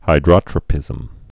(hī-drŏtrə-pĭzəm)